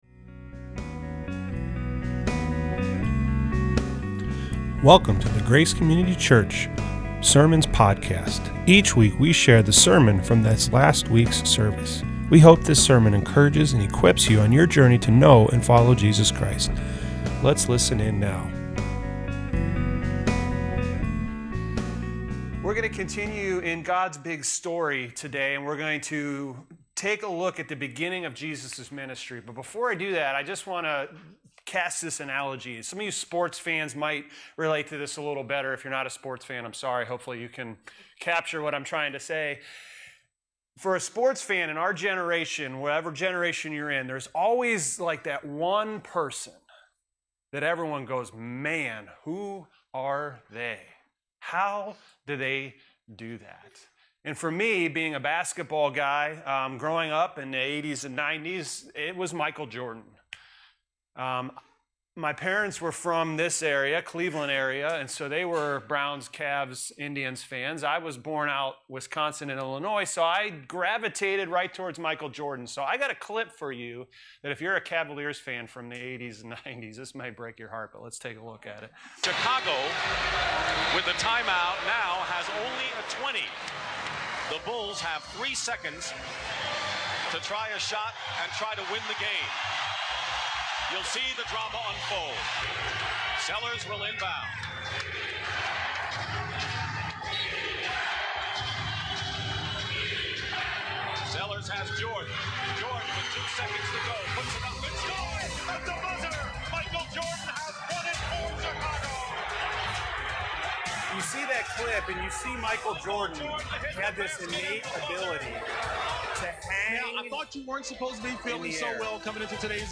Sunday, November 24th, 2024 (SERMON)